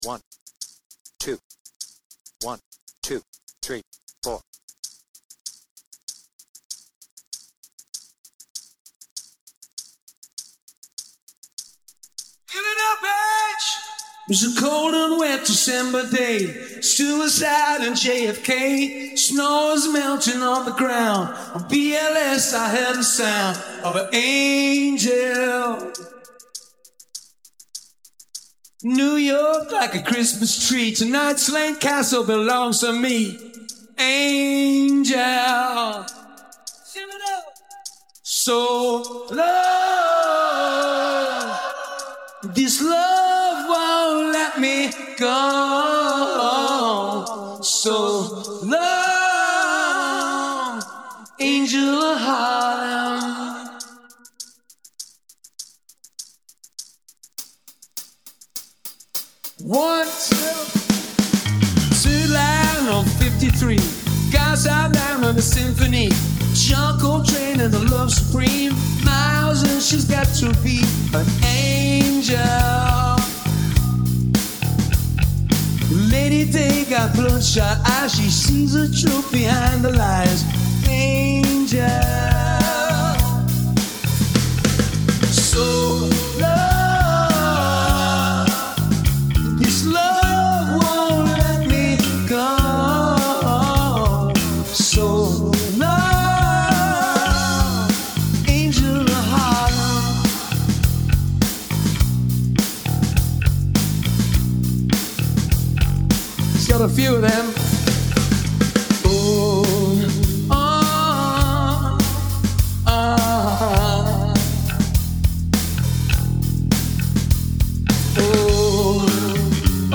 BPM : 83
Tuning : E
With Vocals